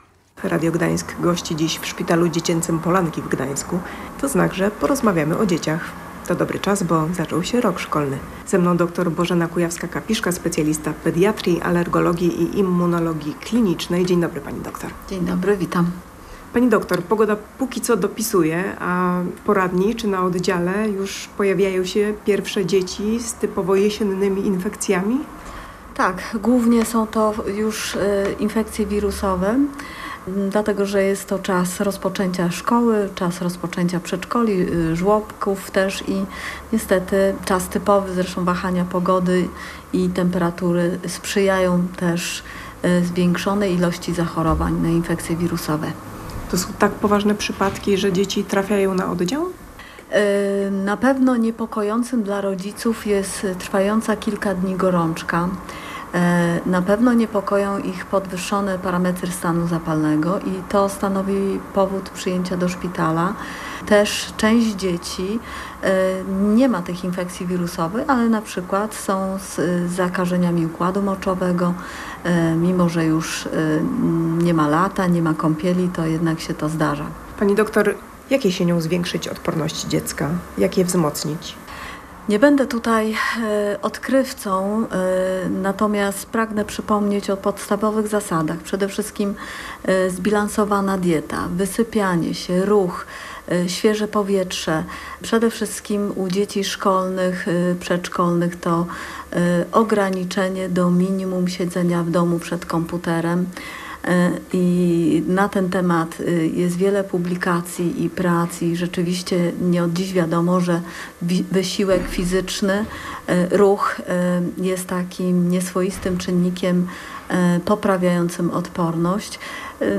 Radio Gdańsk gościło w Szpitalu Dziecięcym Polanki w Gdańsku.